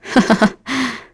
Glenwys-Vox_Happy2.wav